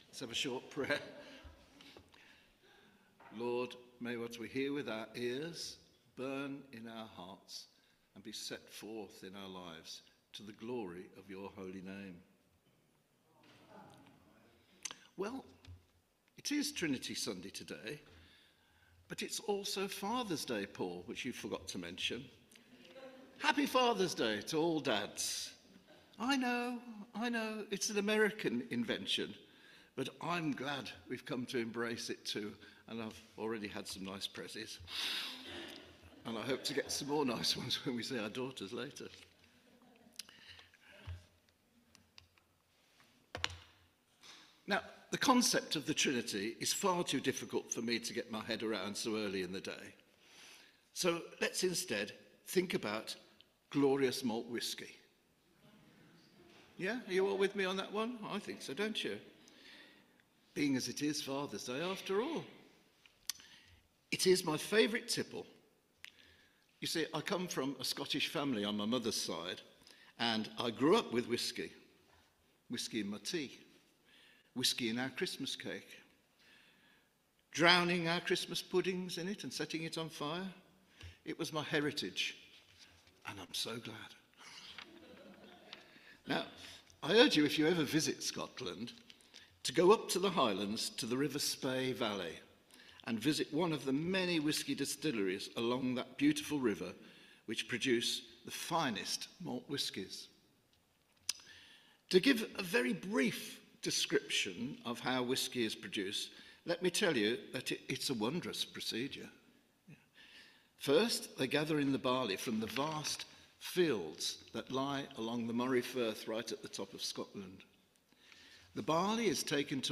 Media for Holy Communion on Sun 15th Jun 2025 09:00 Speaker